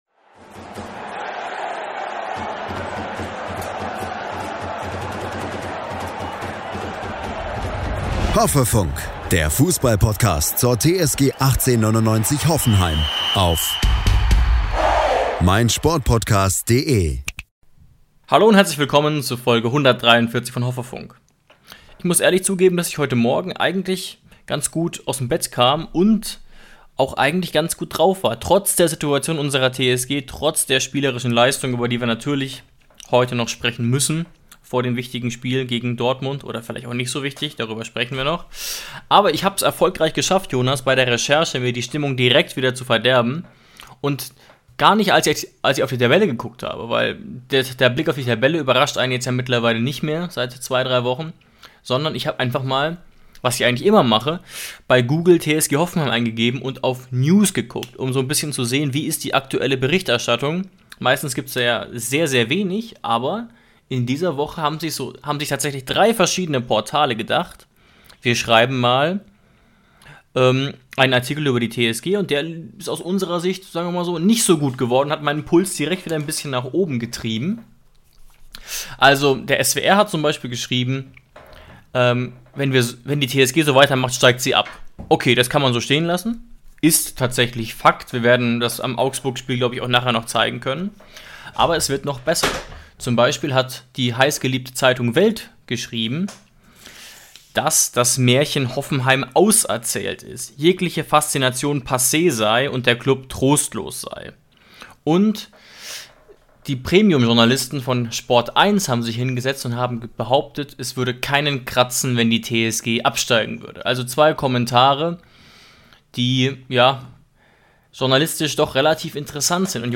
Beschreibung vor 3 Jahren Einige Qualitätsmedien berichten, Hoffenheims Abstieg würde keinen interessieren und das TSG-Märchen sei ausgeträumt – die „Argumente“ dafür analysieren wir heute. Außerdem sprechen wir über die zunehmend schwere Lage, in der die TSG sich befindet. Am Mikrofon sitzen die TSG-Mitglieder und -Fans